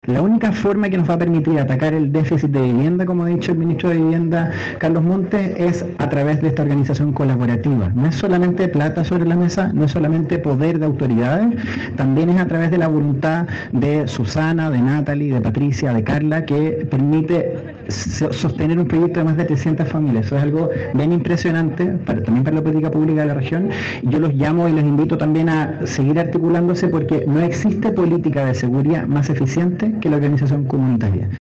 En un maravilloso día de sol, 293 familias curicanas recibieron las escrituras de sus viviendas sociales, documento que las acredita legalmente como propietarias de sus hogares.